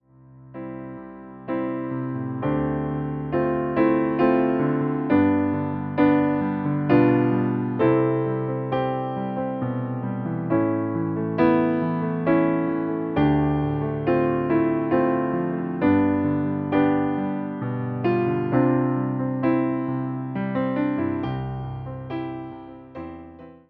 Delikatny akompaniament do kolędy
Wersja demonstracyjna:
67 BPM
C – dur